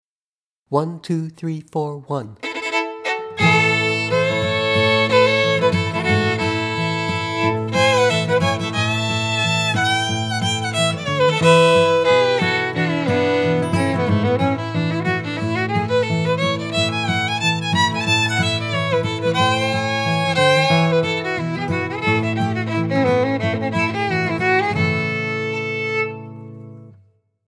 two versions, my arrangements, A